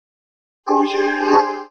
Techno / Voice / VOICEFX182_TEKNO_140_X_SC2.wav